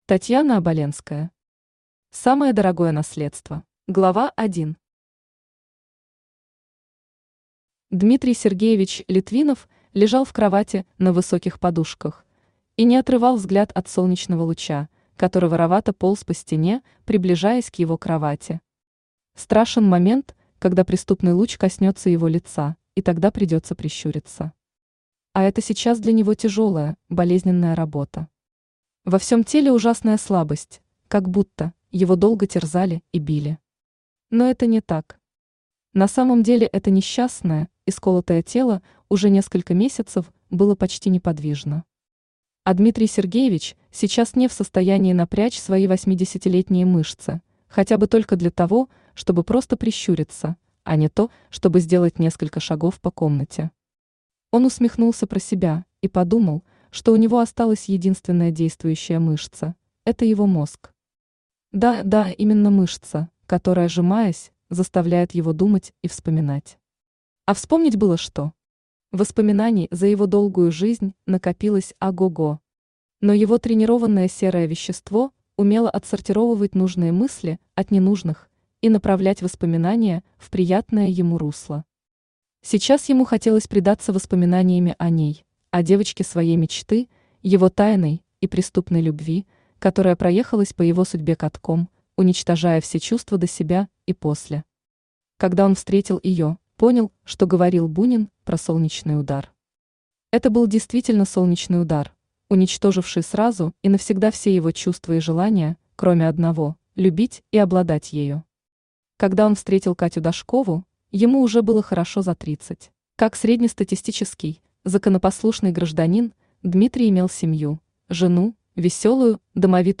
Аудиокнига Самое дорогое наследство | Библиотека аудиокниг